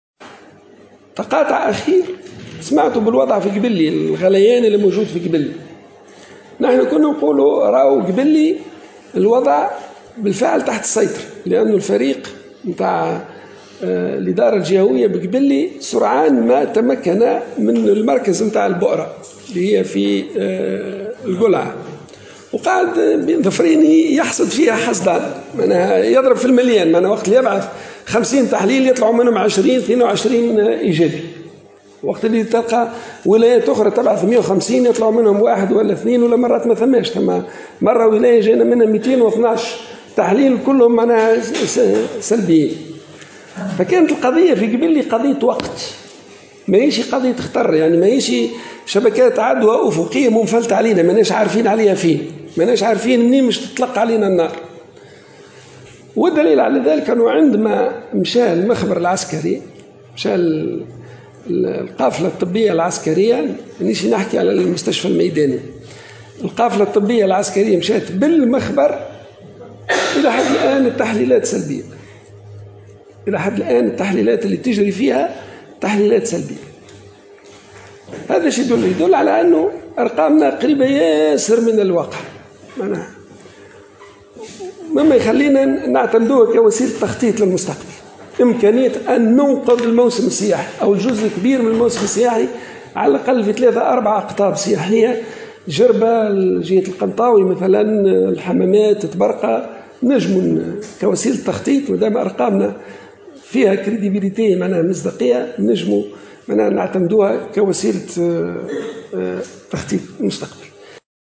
قال وزير الصحة عبد اللطيف المكي خلال جلسة استماع في لجنة الصحة والشؤون الاجتماعية بمجلس النواب، إنّه يمكن إنقاذ الموسم السياحي في أقطاب سياحية على غرار الحمامات وطبرقة والقنطاوي.